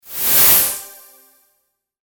/ F｜演出・アニメ・心理 / F-03 ｜ワンポイント1_エフェクティブ
シューウ